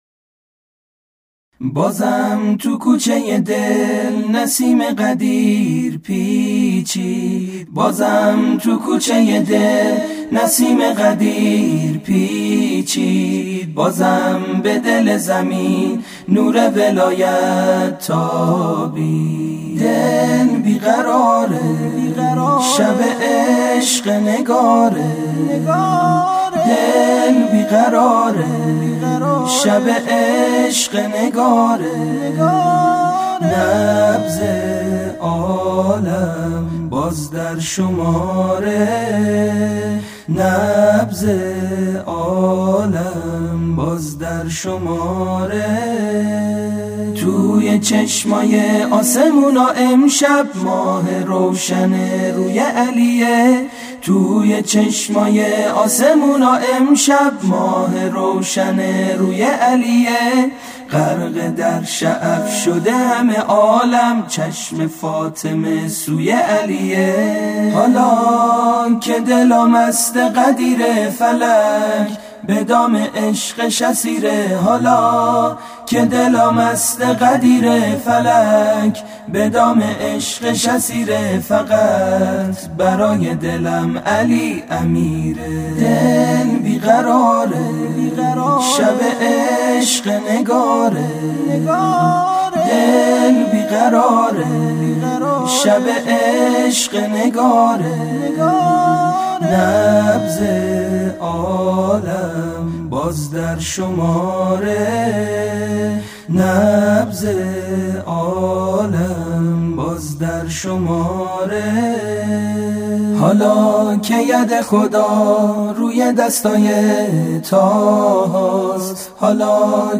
تک آوا
شاد